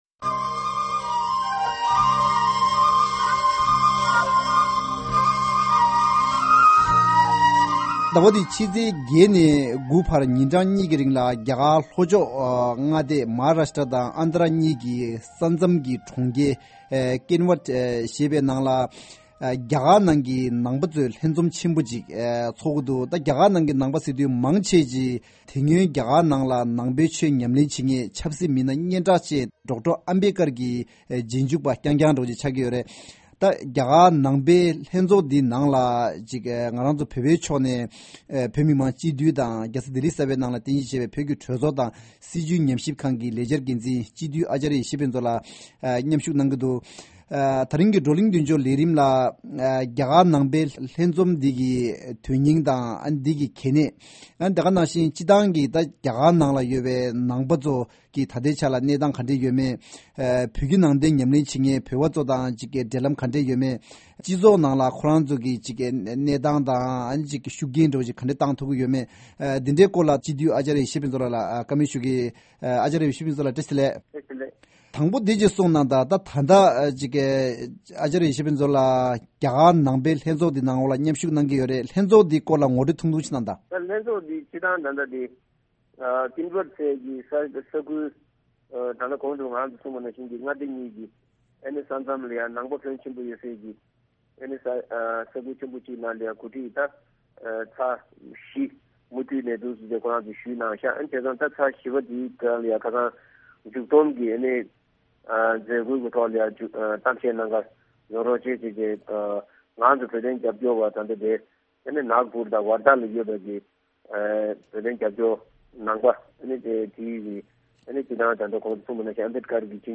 Table Talk invites the Acharya Yeshi Phuntsok, member of the exile Tibetan parliament to discuss the revival and general interest on Buddhism amongst the Indian population and its relation to Tibet.